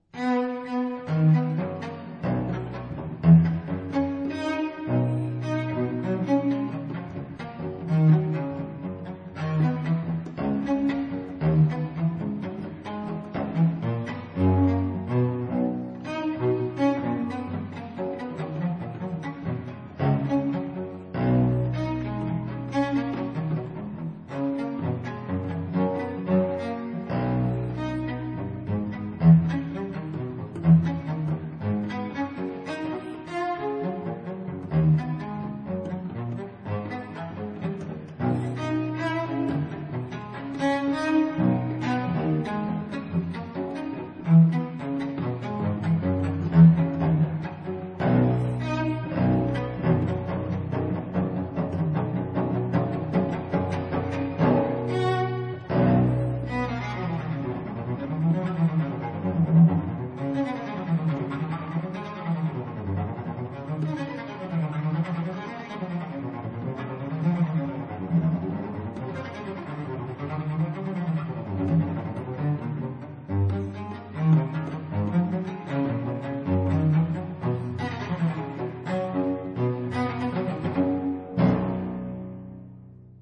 都偏向簡單的架構與簡單的旋律，與簡單的情感表達。
而表達的情感甚多是低沈與悲傷。
另外，這些錄音使用了十把大提琴，
只有一把是古琴，其他九把都是複製。